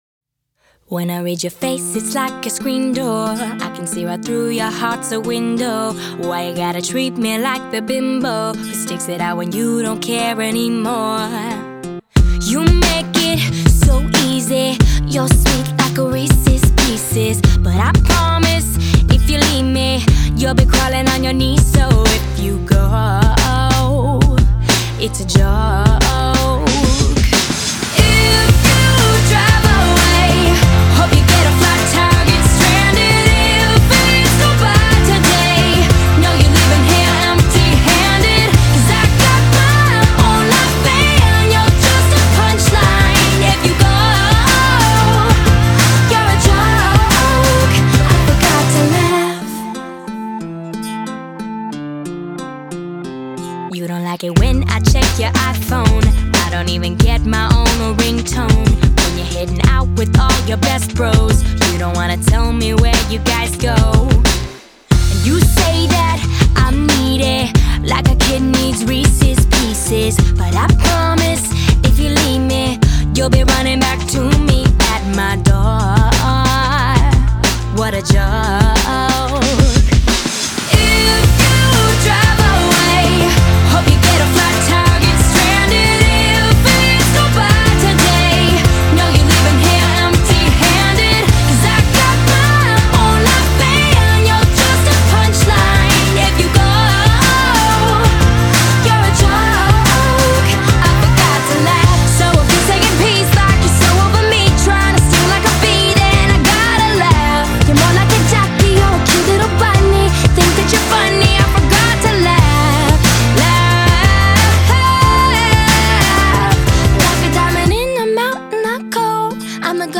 Жанр: Pop; Битрэйт